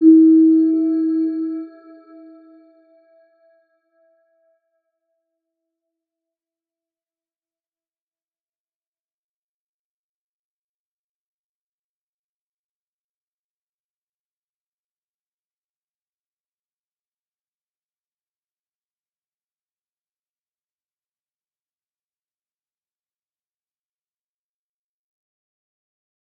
Round-Bell-E4-mf.wav